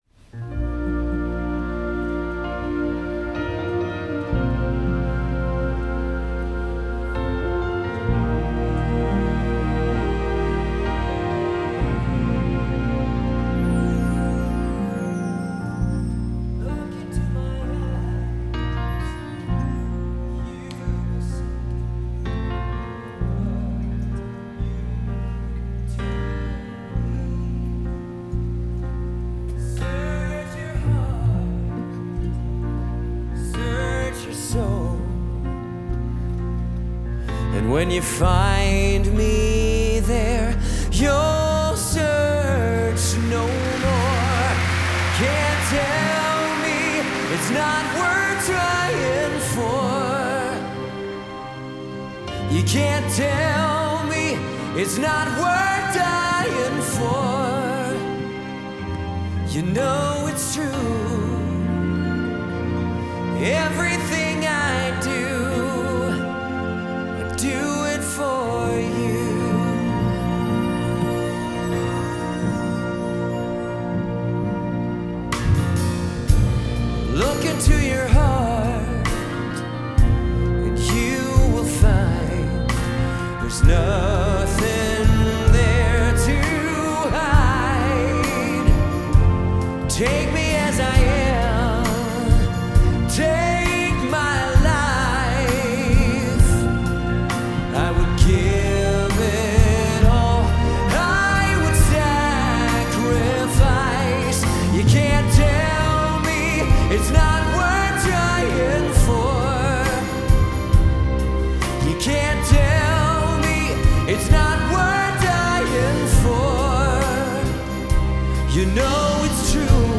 Live-Performance
October 2024 (sound problem at the beginning)